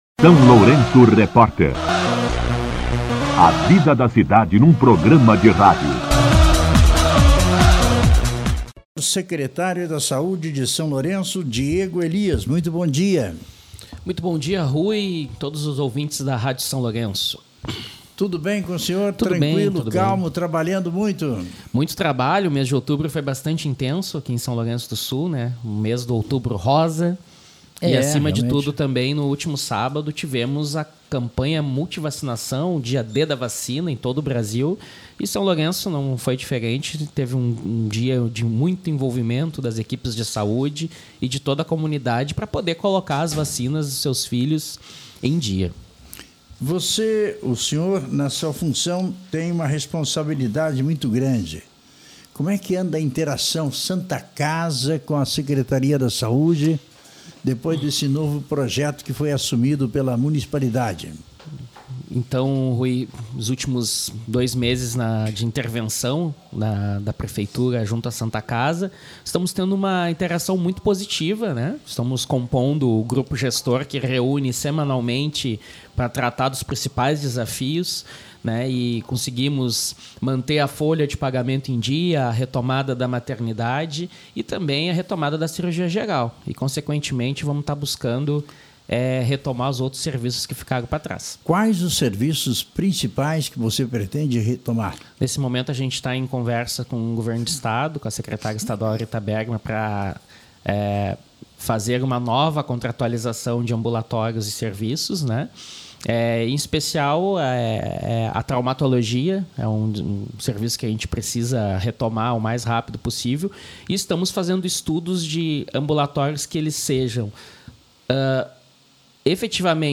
Em entrevista ao SLR RÁDIO, o secretário de Saúde, Diego Elias, destacou a missão de salvar vidas e reforçou a importância de manter a caderneta de vacinação em dia, garantindo proteção e saúde para as crianças e para toda a comunidade.
Entrevista com o secretário de Saúde, Diego Elias